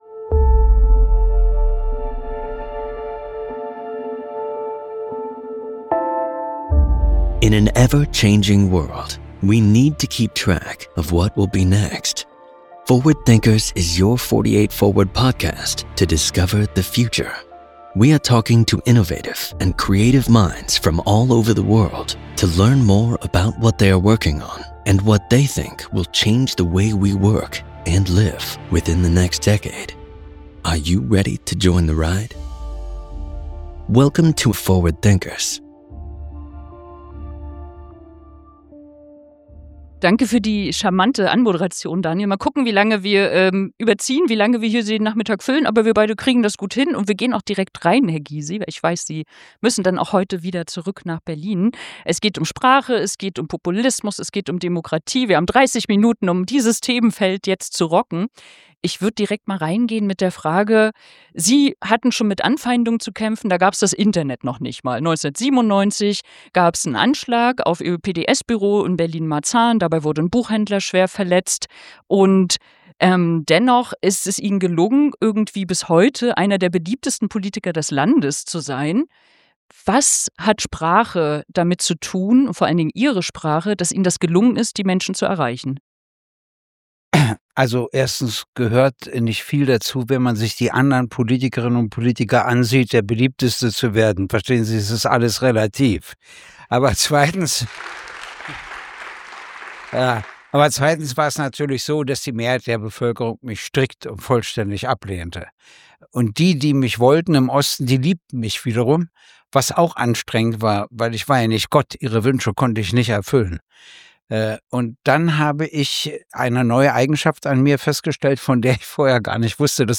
Im Gespräch
auf dem 48forward Festival 2025